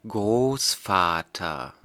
Ääntäminen
Synonyymit ukki pappa isoisä taata vaija Ääntäminen Tuntematon aksentti: IPA: [ˈʋɑːri] Haettu sana löytyi näillä lähdekielillä: suomi Käännös Ääninäyte 1.